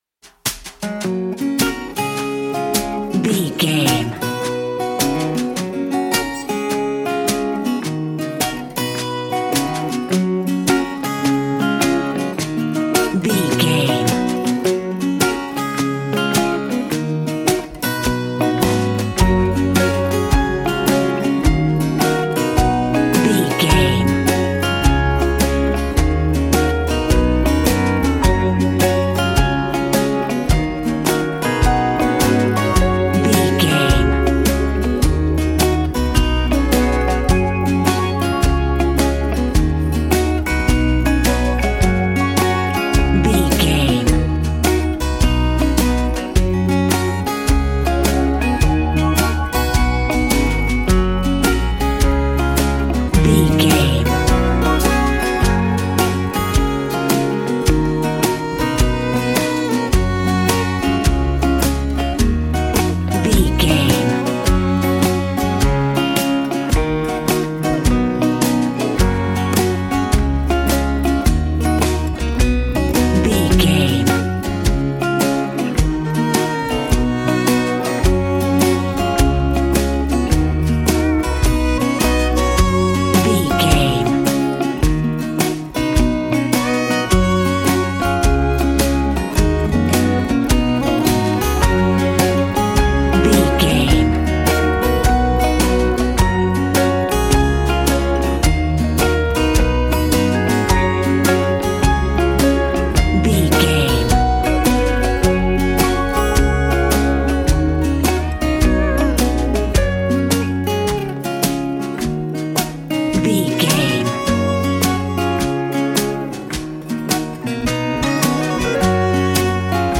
Ionian/Major
drums
acoustic guitar
violin